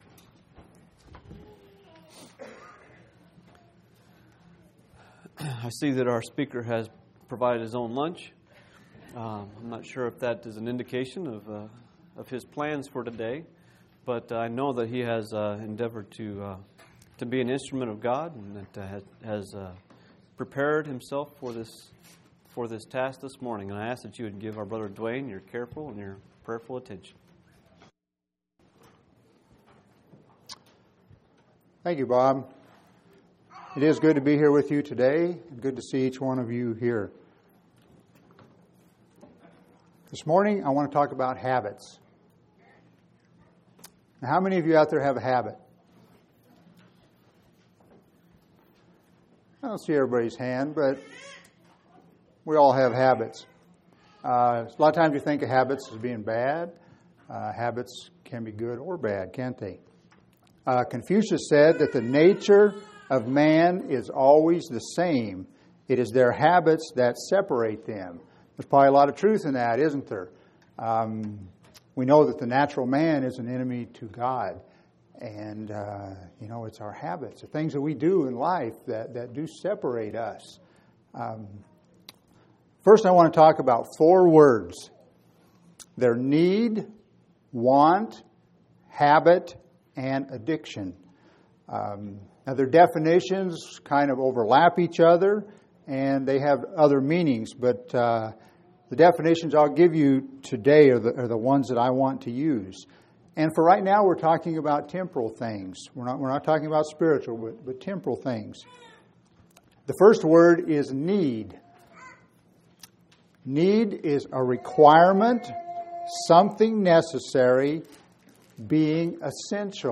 5/29/2005 Location: Phoenix Local Event
audio-sermons